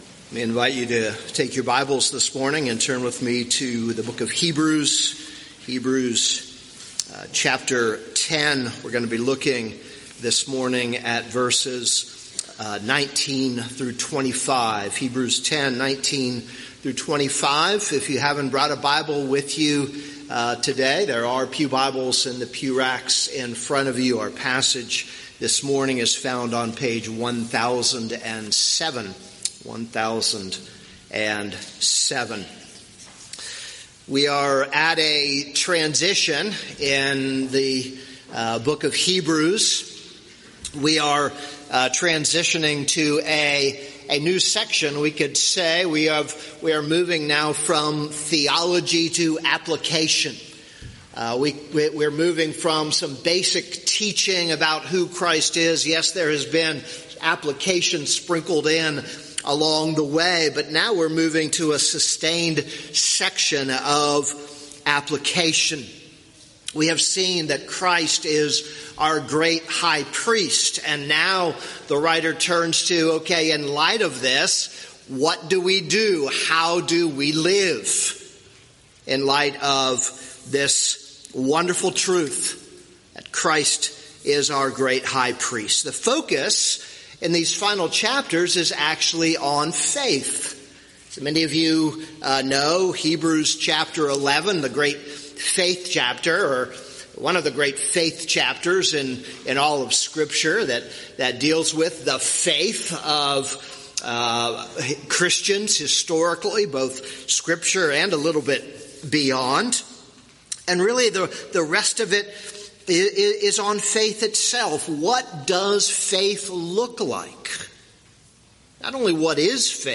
This is a sermon on Hebrews 10:19-25.